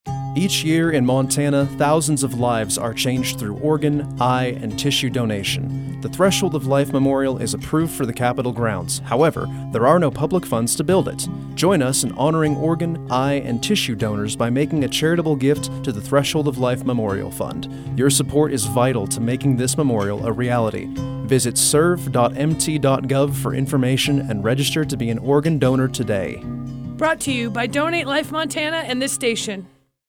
Public Service Announcements
Radio Spots